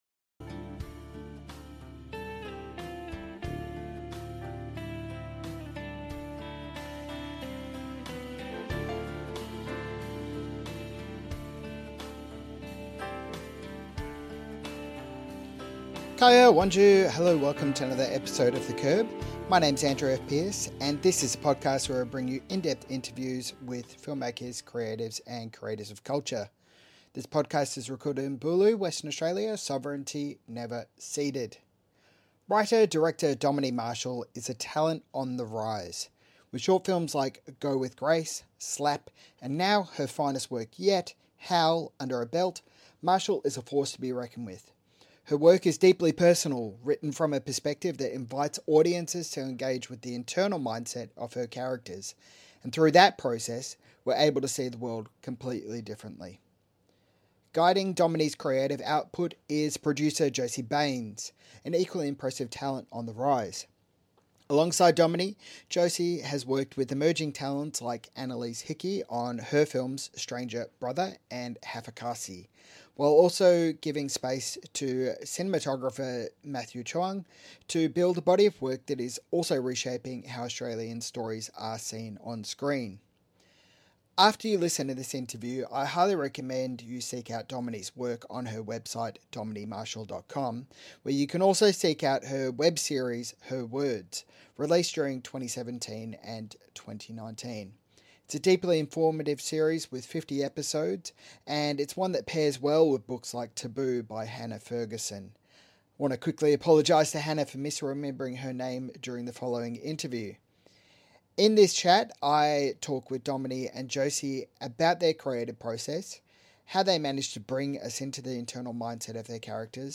MIFF Interview